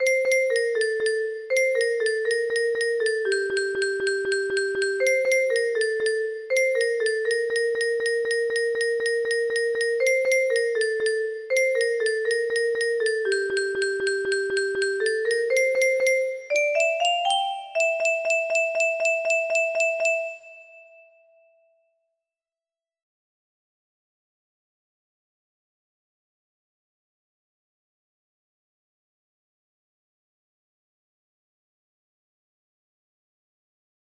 Cantiga da Montaña music box melody